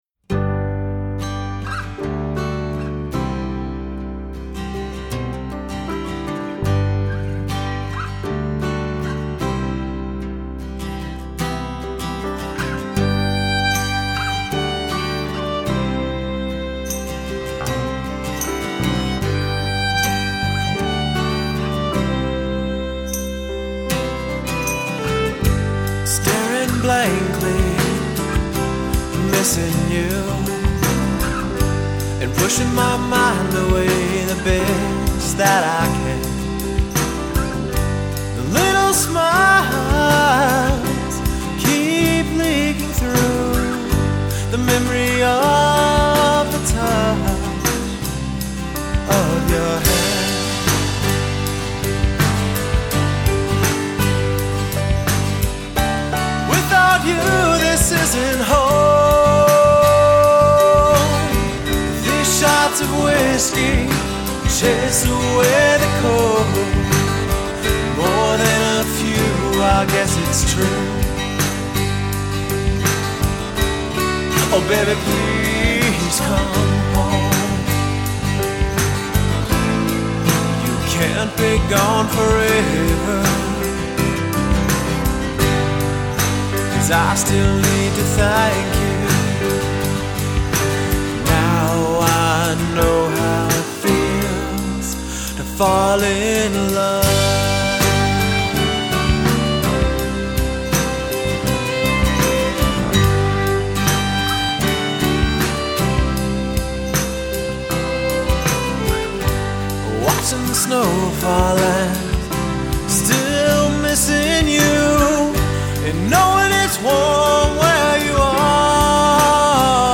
Guitar, Violin, Vocals
Drums, Percussion
Piano
Bass Guitar